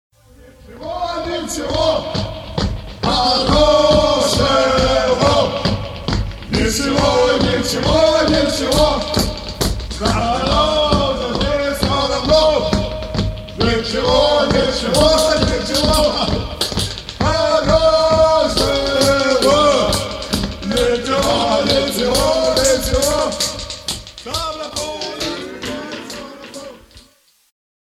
космическая панк-группа